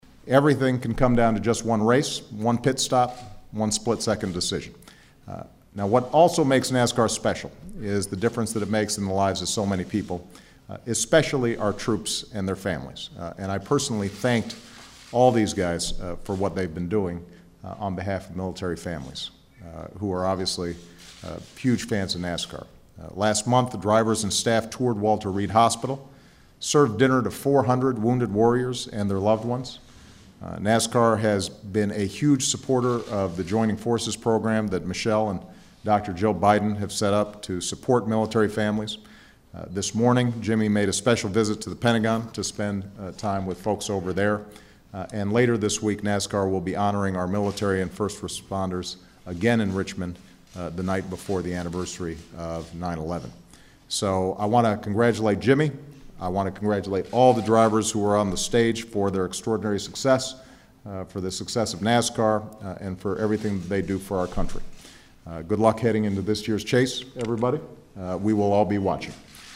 Five time Championship winning driver Jimmie Johnson and much of last year’s top 10 drivers visited the White House on September 7th, 2011 where they were honored for their achievements by President Barack Obama.
President Obama thanks the drivers and NASCAR for their support of military families
2011whitehousebarackobamamilitary.mp3